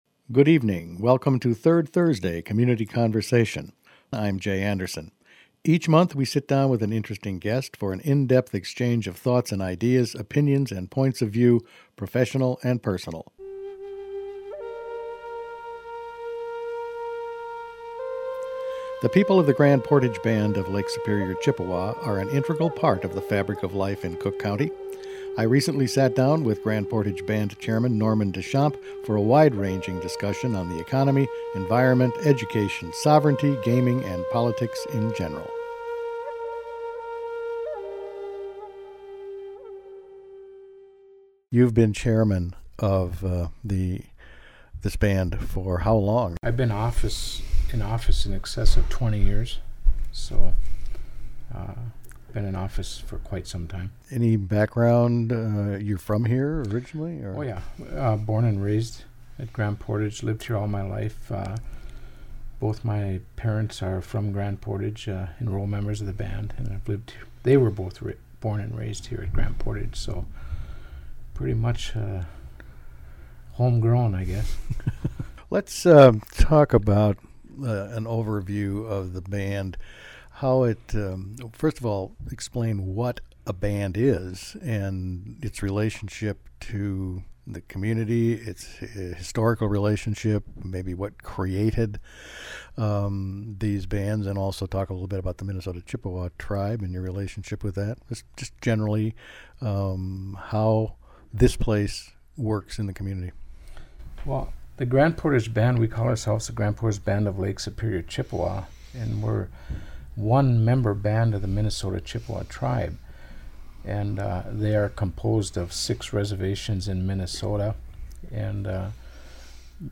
Grand Portage Band Chairman Norman Deschampe on Third Thursday Community Conversation